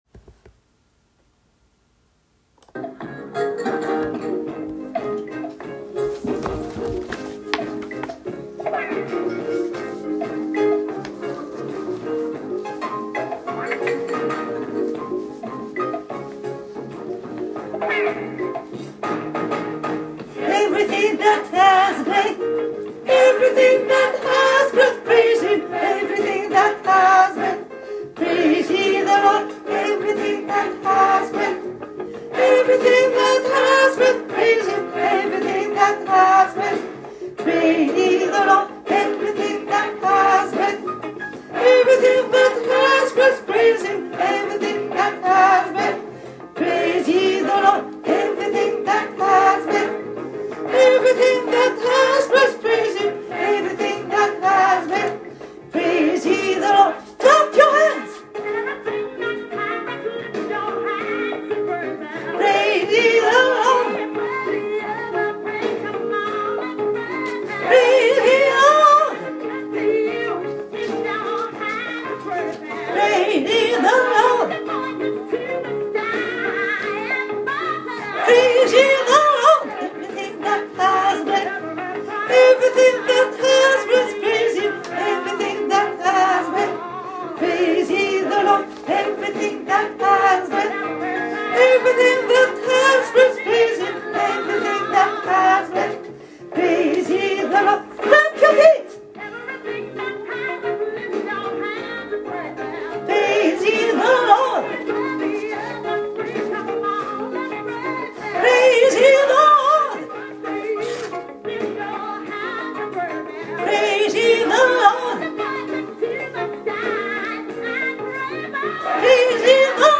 Parole et musique d'entrainement d'everything that hath.
Everything-that-haht-breath-soprano.m4a